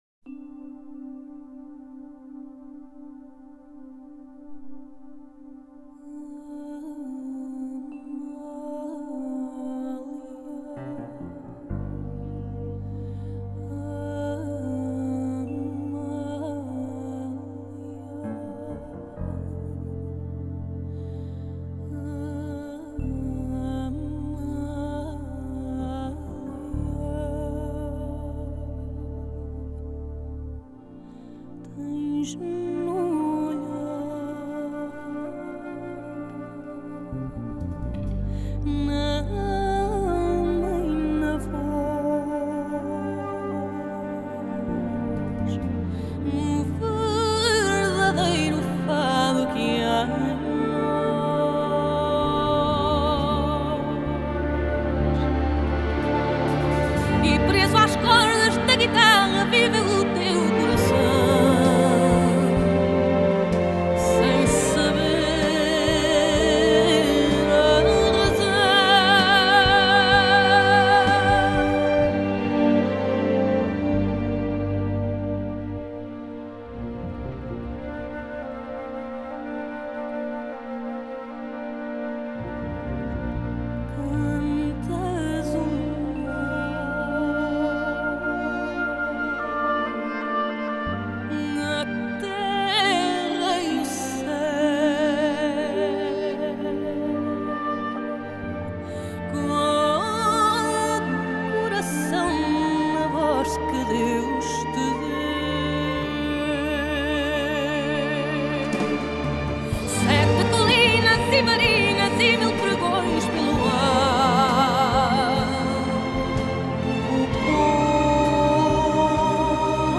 Genre: Score
DSD-layer: Stereo, 5.1 Multichannel.